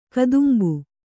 ♪ kadumbu